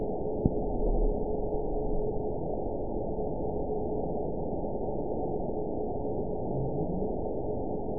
event 922215 date 12/28/24 time 08:57:46 GMT (5 months, 3 weeks ago) score 9.57 location TSS-AB04 detected by nrw target species NRW annotations +NRW Spectrogram: Frequency (kHz) vs. Time (s) audio not available .wav